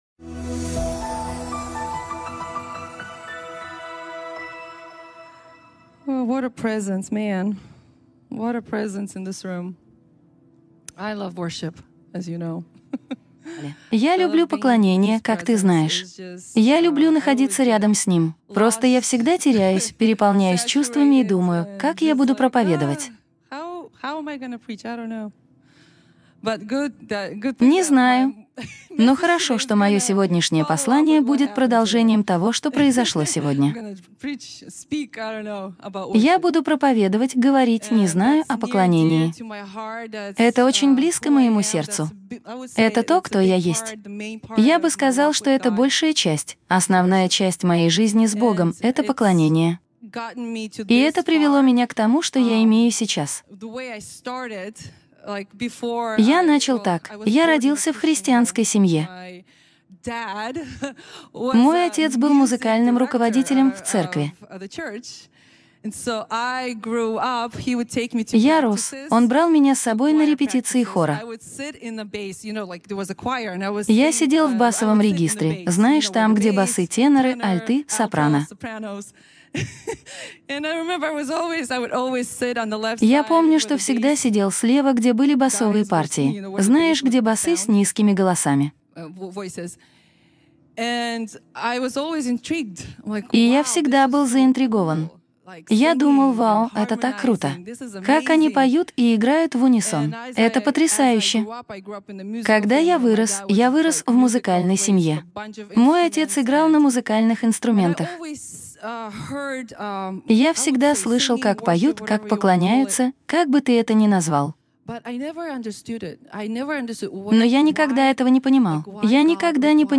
(Перевод с Английского)
Проповеди